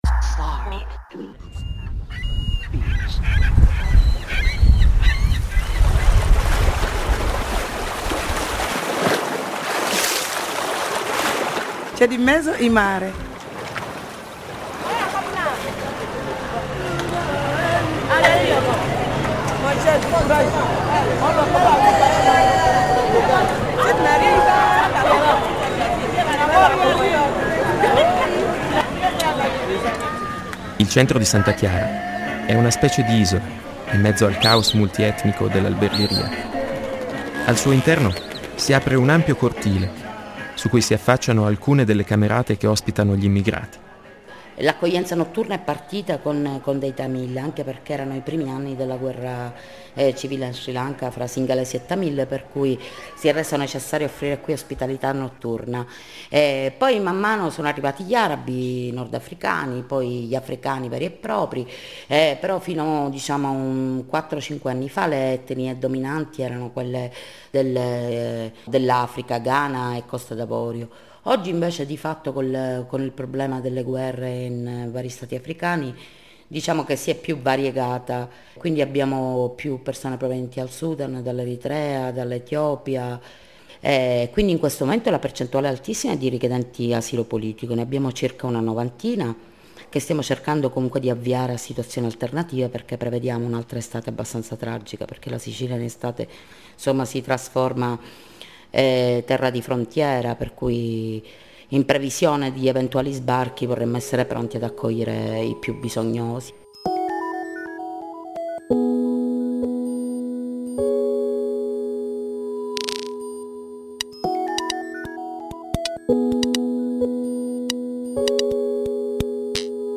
radio doc about life stories of migrants and asylum-seekers living in the Palermitan neighborough of Albergheria, Sicily.